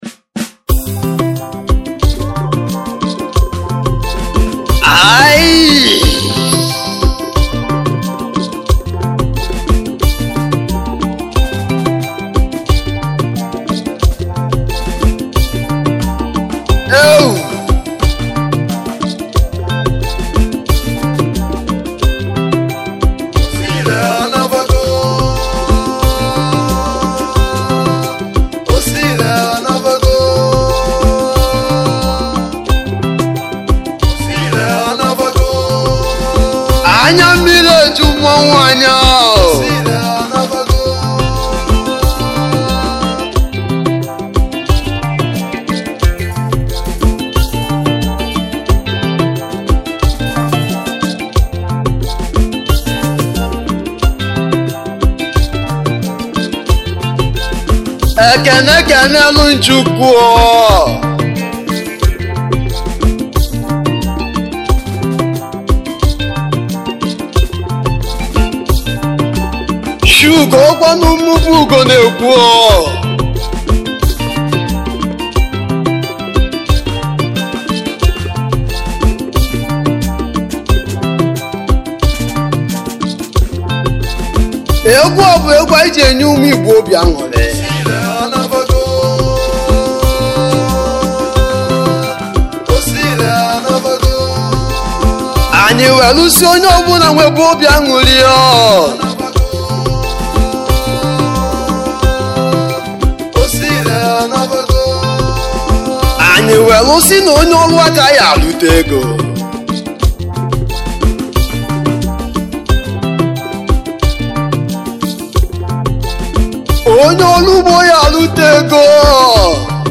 igbo highlife